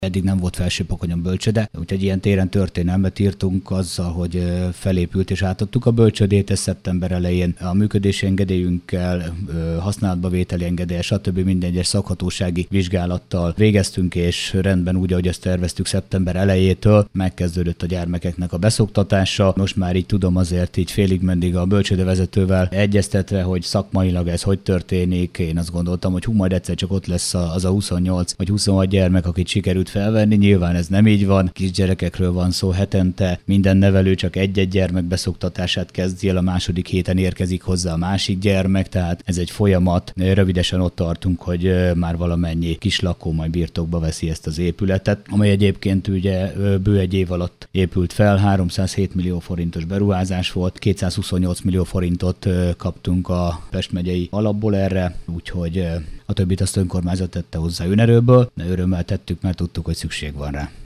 Történelmet írt Felsőpakony a bölcsőde felépítésével - fogalmazott Nagy János polgármester. Az intézmény jelentős állami támogatással épült meg, a gyermekek beszoktatása a végéhez közeledik.